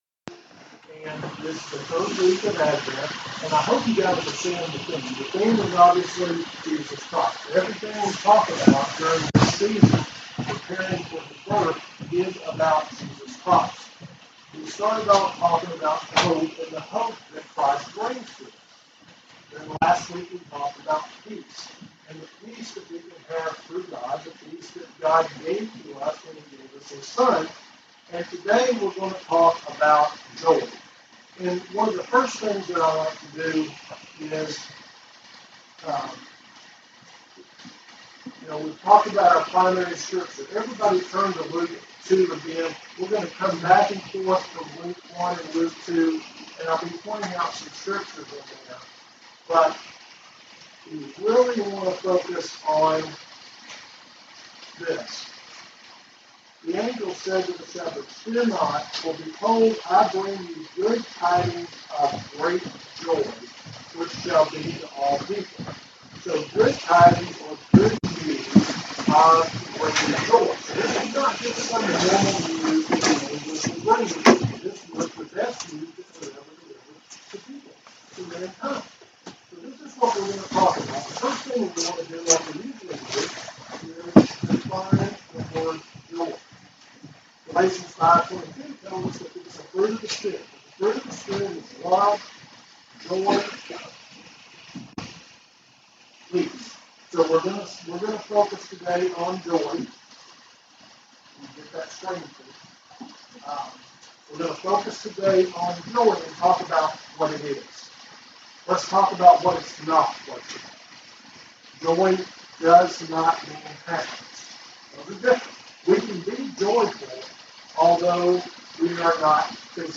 Sermon Synopsis